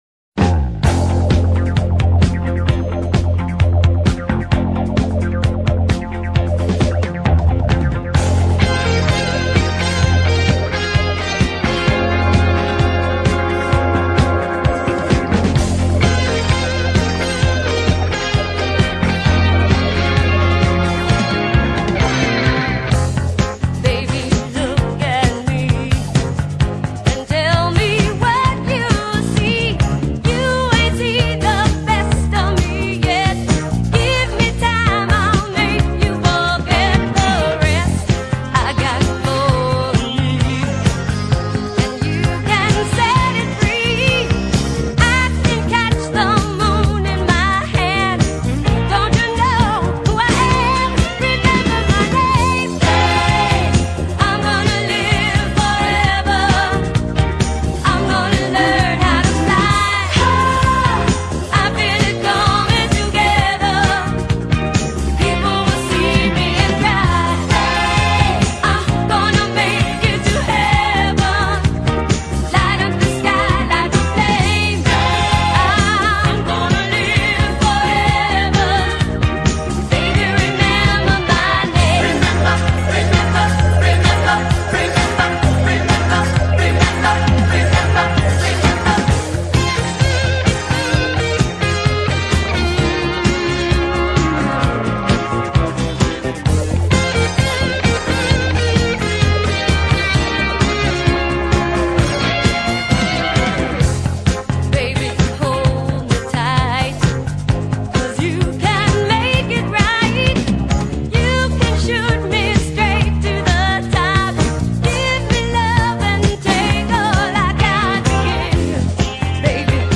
Rapper & Dancer with migration background Tyrone.